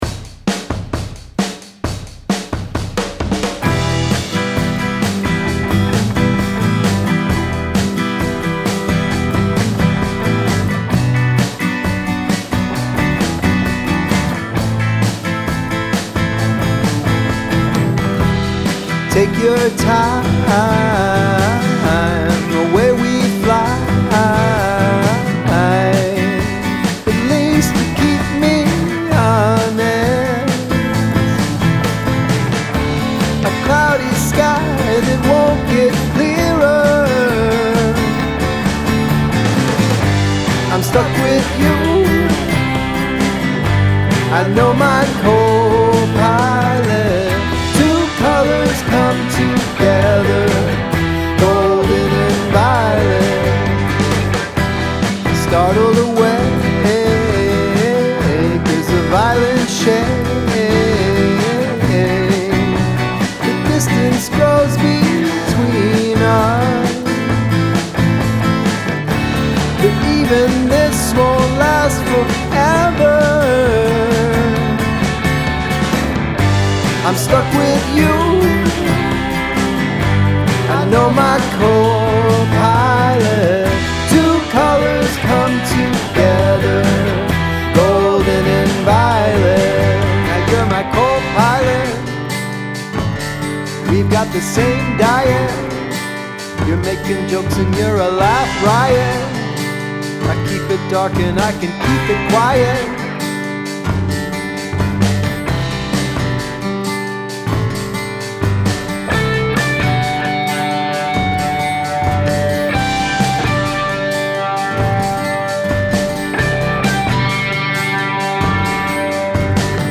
Guest Lead Vocals